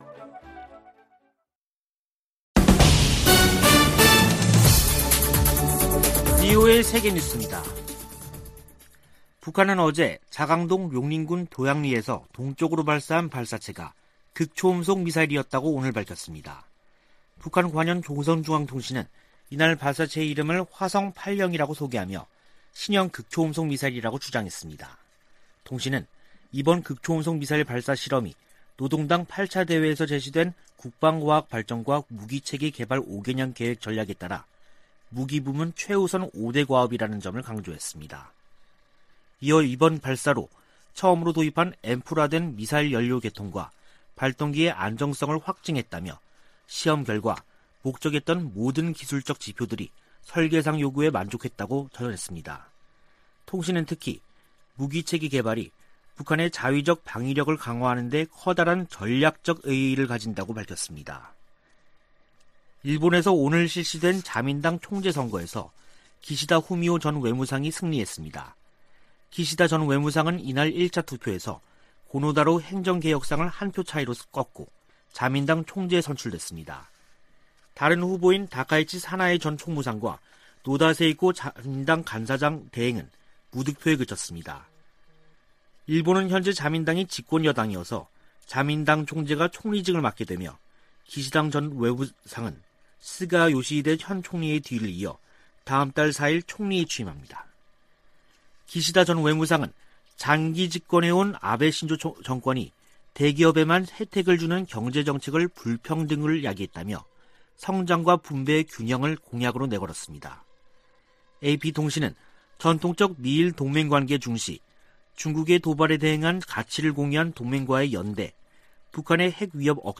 VOA 한국어 간판 뉴스 프로그램 '뉴스 투데이', 2021년 9월 29일 3부 방송입니다. 미국 국무부 고위 관리가 북한의 최근 탄도미사일 발사들을 우려하며 규탄한다고 밝혔습니다. 북한이 유화적 담화를 내놓은 뒤 미사일을 발사한 것은 대미 협상에서 우위를 확보하기 위한 것으로 미 전직 관리들은 분석했습니다. 국제사회는 북한의 탄도미사일 발사를 규탄하고, 불법 행위를 멈출 것을 촉구했습니다.